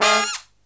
FALL HIT13-R.wav